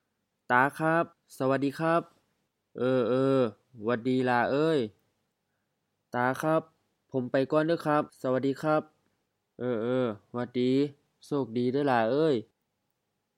BCF02 Greetings and leave takings (formal) — Dialogue A